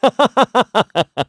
Clause_ice-Vox_Happy4_kr.wav